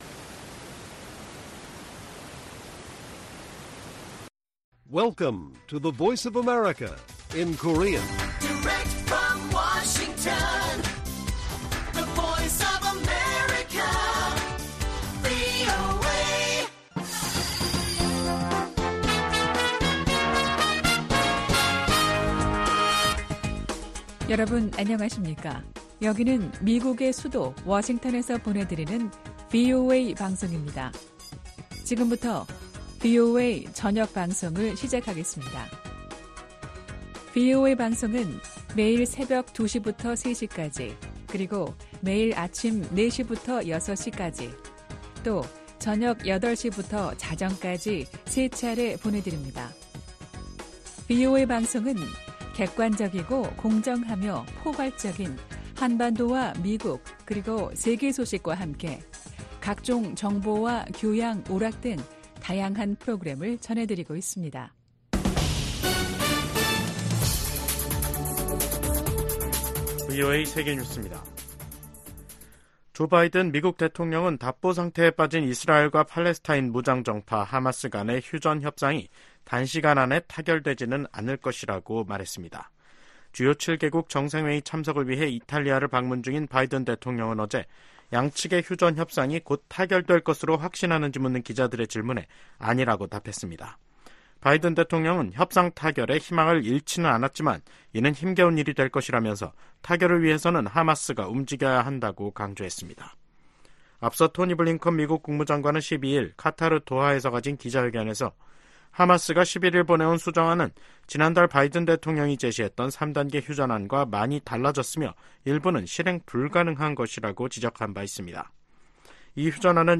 VOA 한국어 간판 뉴스 프로그램 '뉴스 투데이', 2024년 6월 14일 1부 방송입니다. 블라디미르 푸틴 러시아 대통령의 방북 임박설 속에 김일성 광장에 ‘무대’ 추정 대형 구조물이 등장했습니다. 미국의 전문가들은 푸틴 러시아 대통령의 방북이 동북아시아의 안보 지형을 바꿀 수도 있다고 진단했습니다. 미국 정부가 시행하는 대북 제재의 근거가 되는 ‘국가비상사태’가 또다시 1년 연장됐습니다.